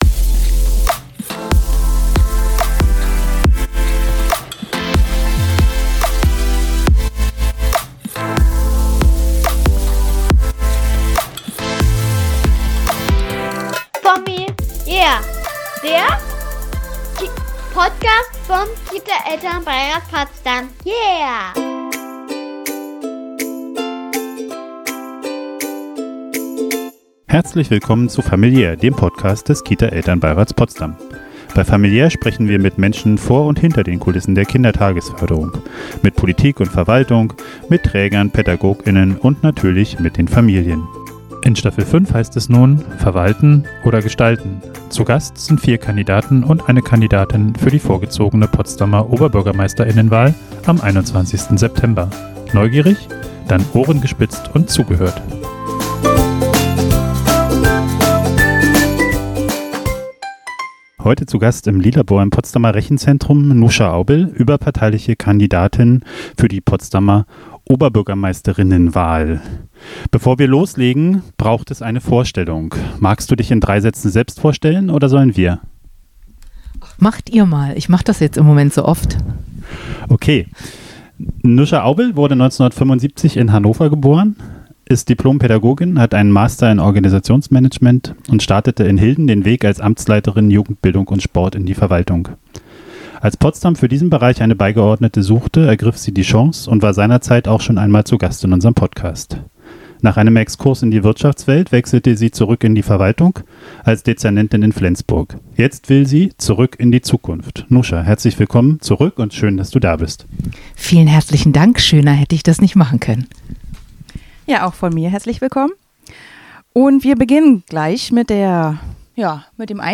Beschreibung vor 8 Monaten Zur fünften Staffel begrüßen wir vier Kandidaten und eine Kandidatin zur vorgezogenen Potsdamer Oberbürgermeister*innenwahl im Lilabor im Rechenzentrum. Freut euch auf über zwei Stunden Austausch – natürlich über Familien- und Kita-Themen, aber auch darüber hinaus, aufgeteilt in fünf badewannentaugliche Interviews.